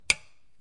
橱柜，门，按钮 1 " 橱柜门锁 点击2
描述：橱柜闩锁点击
Tag: 点击 锁定 关闭 橱柜 关闭